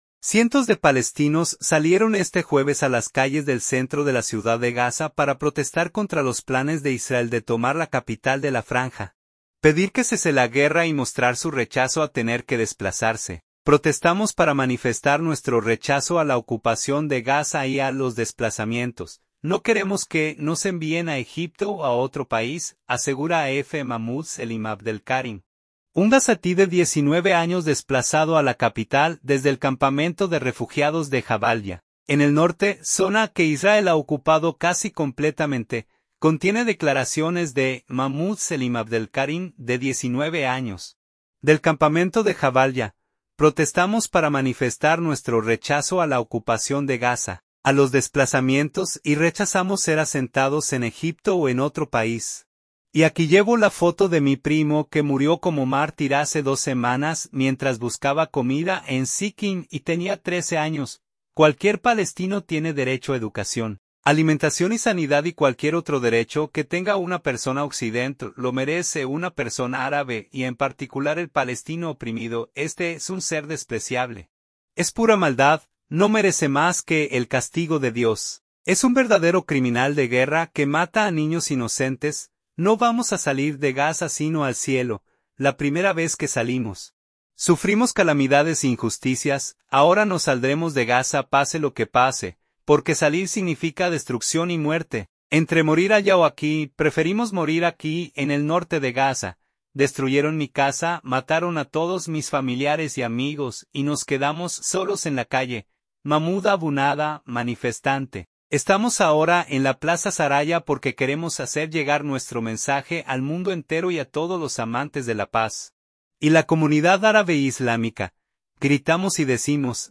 CONTIENE DECLARACIONES DE: